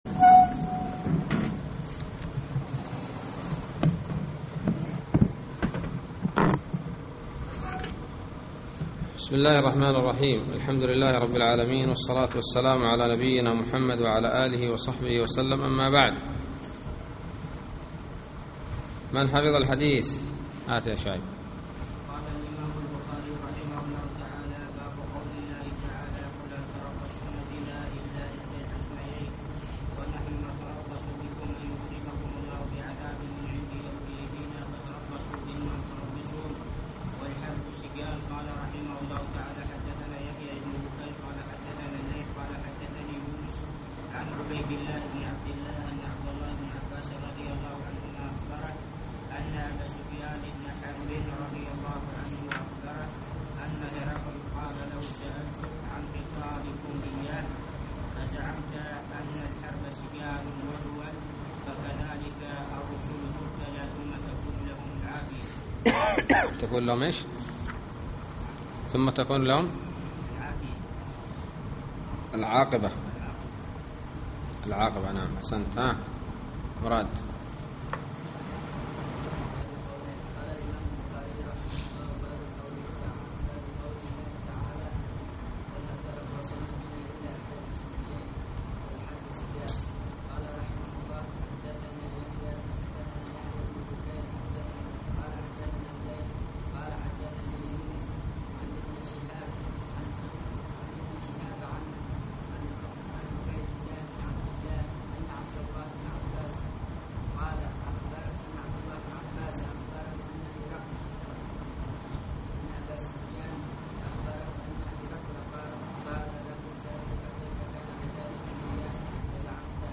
الدرس الثالث عشر من كتاب الجهاد والسير من صحيح الإمام البخاري